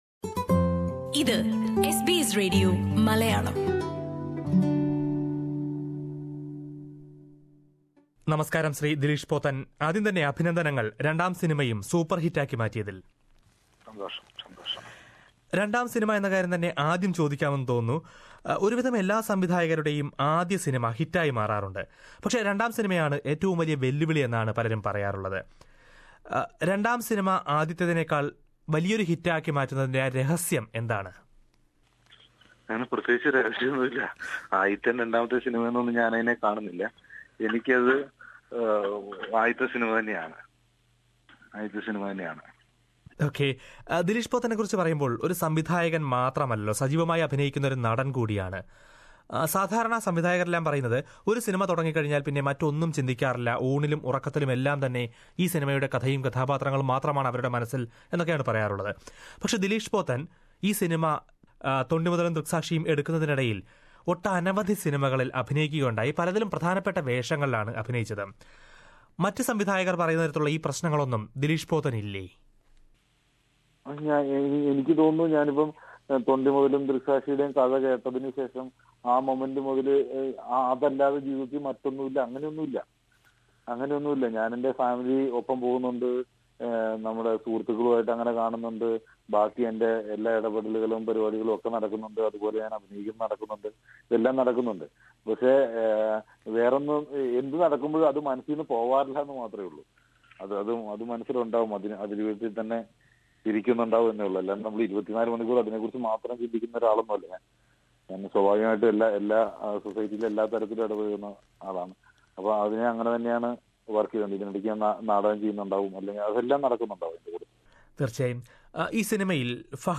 രണ്ടാം സിനിമയിലും പ്രേക്ഷകരെ അത്ഭുതപ്പെടുത്തിയിരിക്കുകയാണ് സംവിധായകന്‍ ദിലീഷ് പോത്തന്‍. തിയറ്ററുകളില്‍ തരംഗമാകുന്ന തൊണ്ടിമുതലും ദൃക്‌സാക്ഷിയും എന്ന ചിത്രത്തെക്കുറിച്ച് ദിലീഷ് പോത്തന്‍ എസ് ബി എസ് മലയാളത്തോട് മനസു തുറക്കുന്നു...